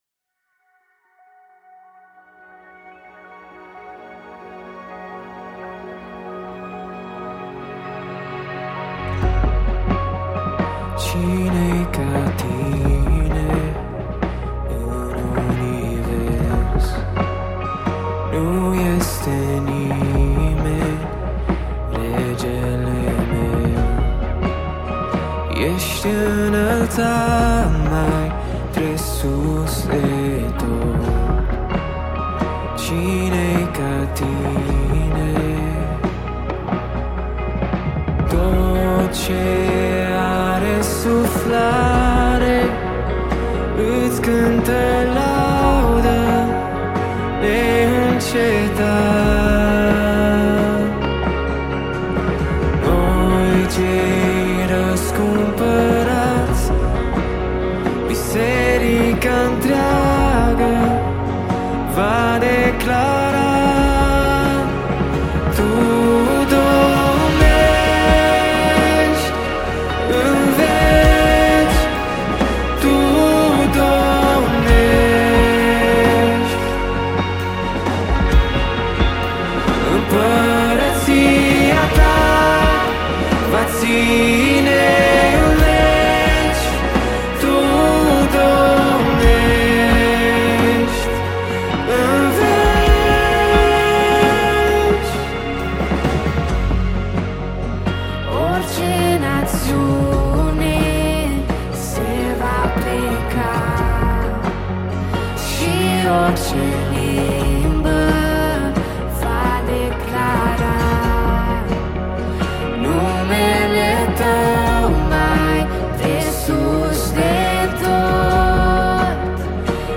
207 просмотров 103 прослушивания 4 скачивания BPM: 130